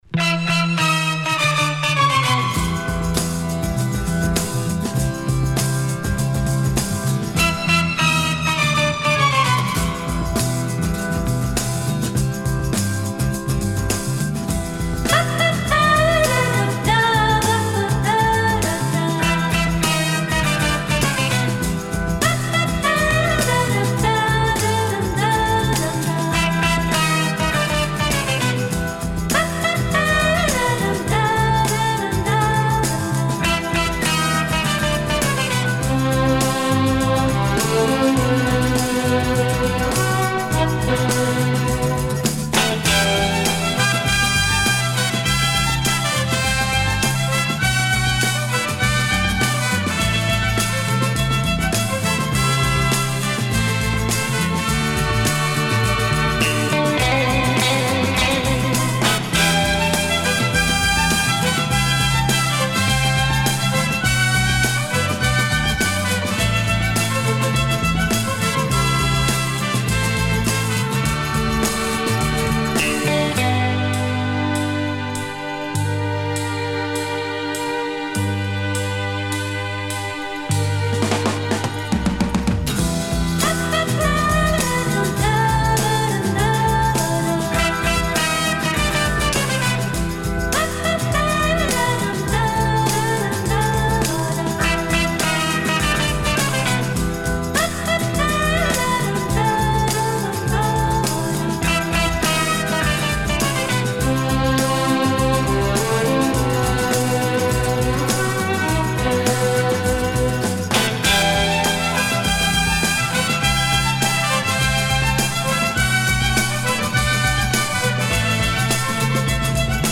Всюду звучат, затаившие дыхание, женские вокалы.
Genre: Instrumental Pop, Back Vocal,
Easy Listening